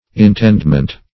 Intendment \In*tend"ment\, n. [OE. entendement understanding,